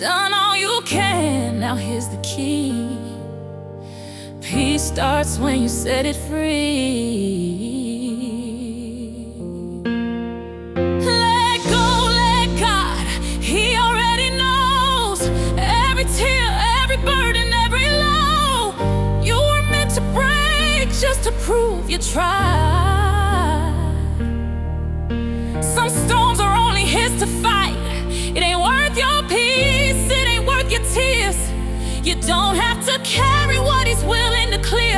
Жанр: R&B / Электроника / Соул